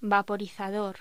Locución: Vaporizador
voz